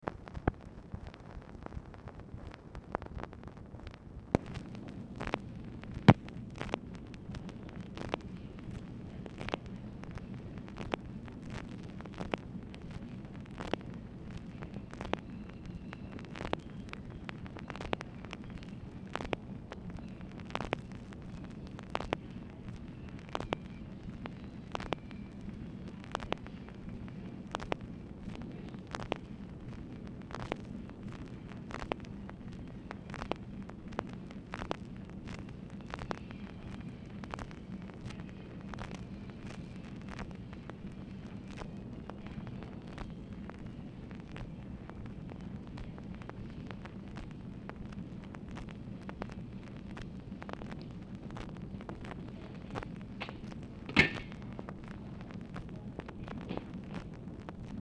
Telephone conversation # 10413, sound recording, OFFICE NOISE, 7/19/1966, time unknown | Discover LBJ
Format Dictation belt
Location Of Speaker 1 Oval Office or unknown location